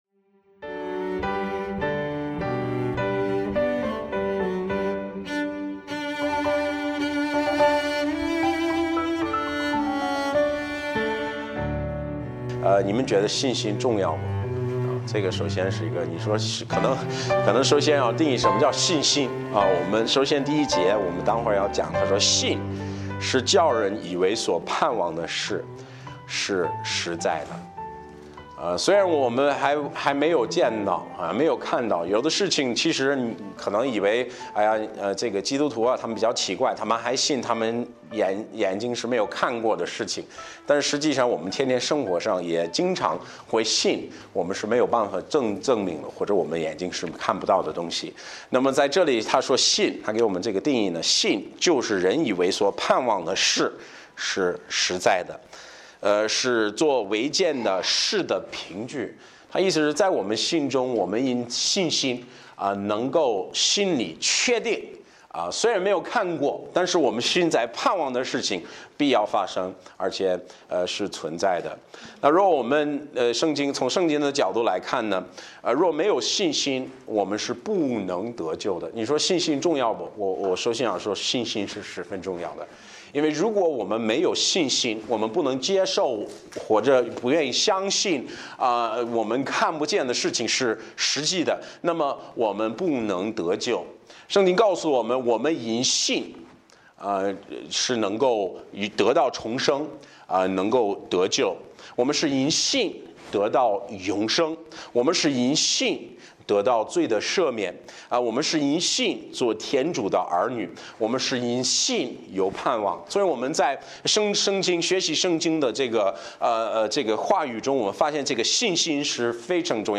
Series: 周日礼拜